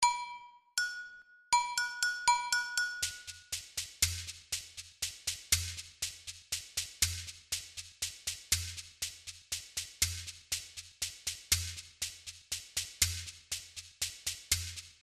Chékeres
Practice in 6/8